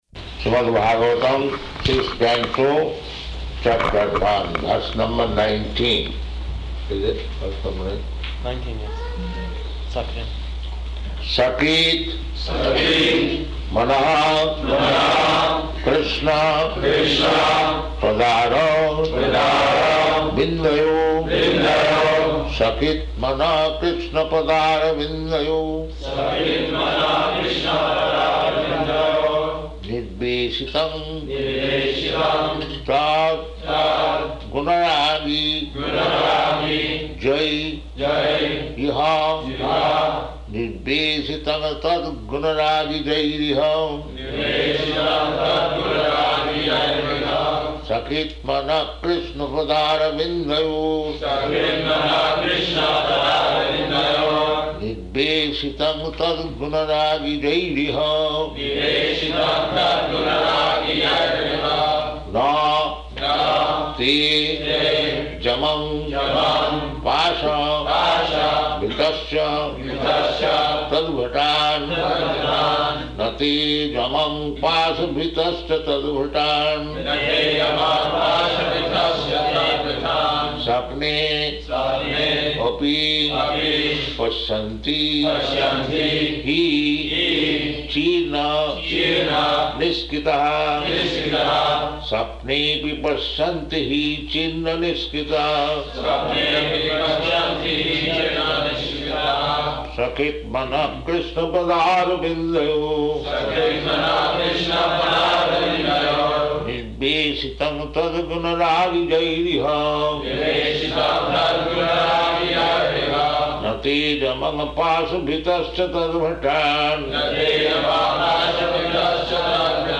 Śrīmad-Bhāgavatam 6.1.19 --:-- --:-- Type: Srimad-Bhagavatam Dated: May 19th 1976 Location: Honolulu Audio file: 760519SB.HON.mp3 Prabhupāda: Śrīmad-Bhāgavatam, Sixth Canto, Chapter One, verse number nineteen.